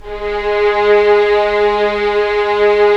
Index of /90_sSampleCDs/Roland - LA Composer vol. 4/STR_TIDE 01/STR_'Violins _